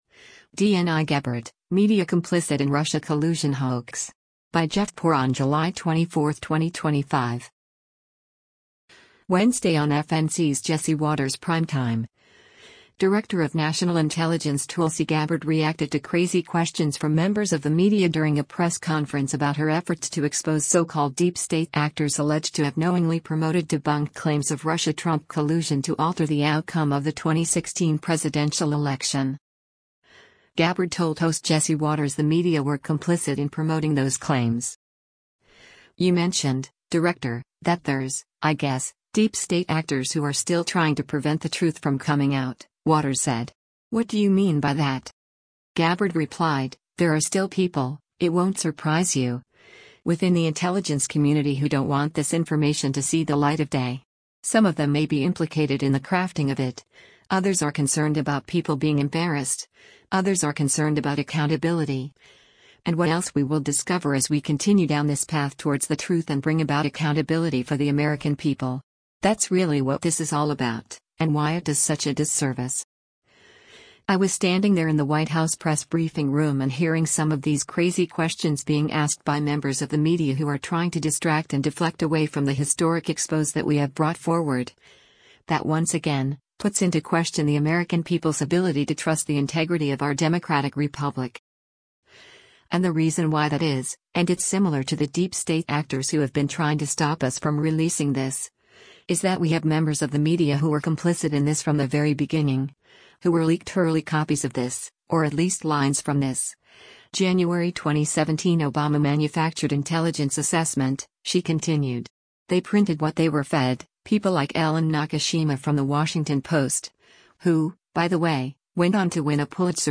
Gabbard told host Jesse Watters the media were “complicit” in promoting those claims.